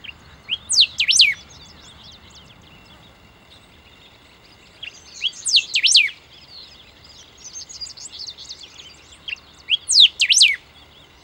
Bico-duro (Saltator aurantiirostris)
Nome em Inglês: Golden-billed Saltator
Condição: Selvagem
Certeza: Gravado Vocal